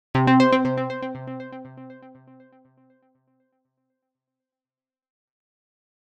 Scifi 1.mp3